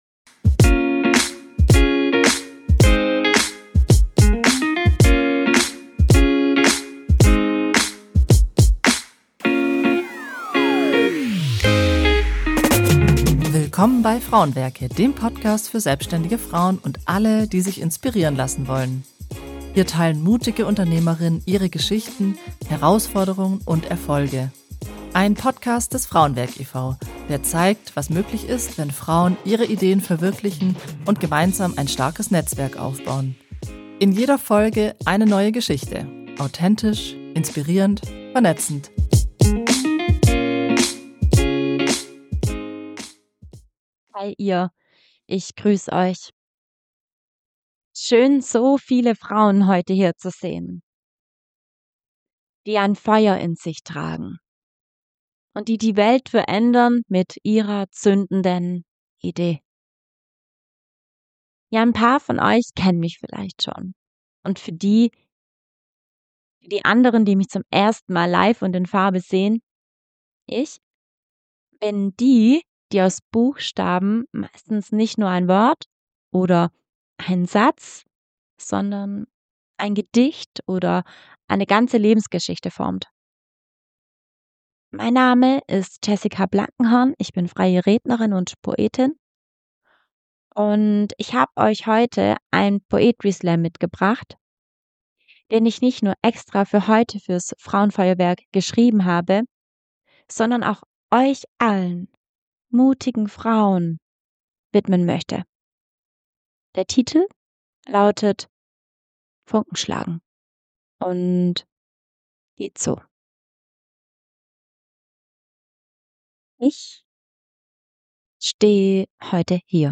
Beschreibung vor 4 Monaten Der Podcast berichtet live vom Frauenfeuerwerk in Dettingen, einer Veranstaltung für Gründerinnen, Unternehmerinnen und kreative Frauen.